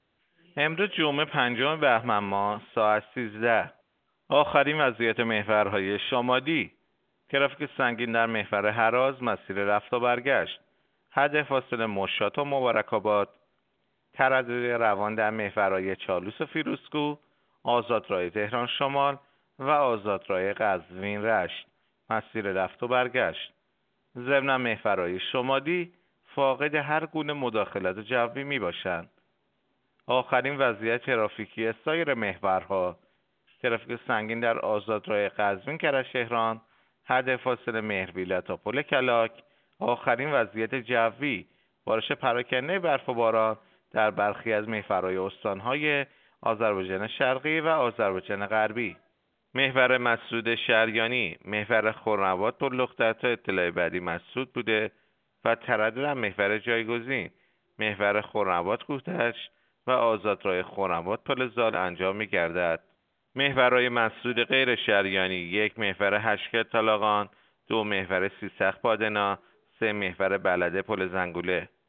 گزارش رادیو اینترنتی از آخرین وضعیت ترافیکی جاده‌ها ساعت۱۳ پنجم بهمن؛